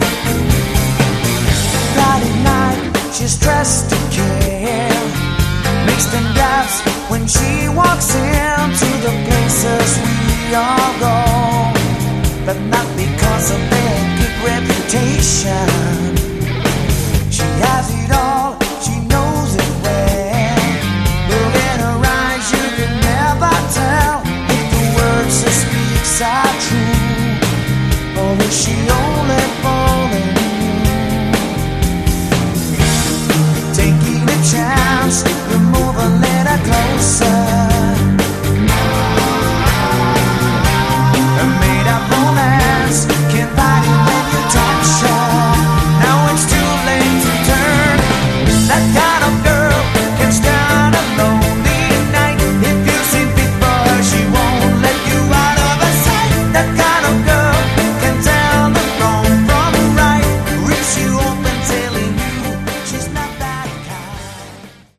Category: AOR/Westcoast
Vocals
Guitar
Drums
Bass
Keyboards